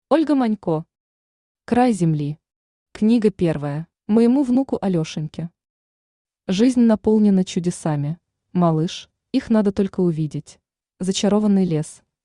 Аудиокнига Край Земли. Книга первая | Библиотека аудиокниг
Книга первая Автор Ольга Владимировна Манько Читает аудиокнигу Авточтец ЛитРес.